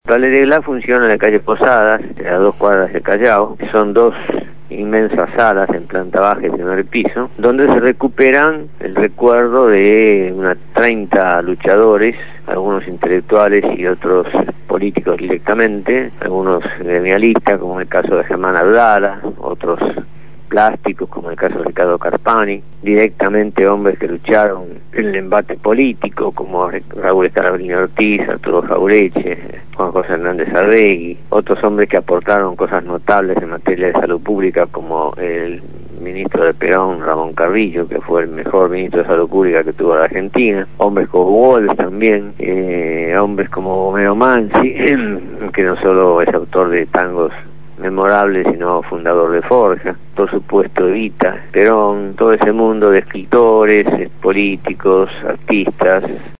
El historiador Norberto Galasso evocó a Enrique Santos Discépolo en el programa «Esperanza para todos» (Miércoles 14 a 16 hs.) por Radio Gráfica